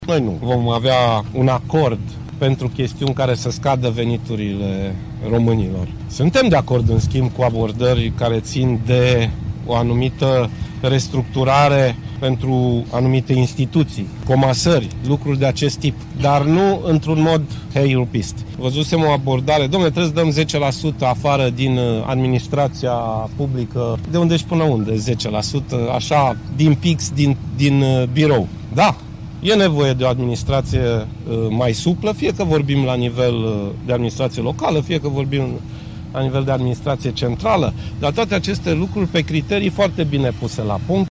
Prezent astăzi pe şantierul autostrăzii din zona Margina, ministrul Transporturilor, Sorin Grindeanu, a dat asigurări că lucrările nu vor fi afectate de situaţia economică actuală din România.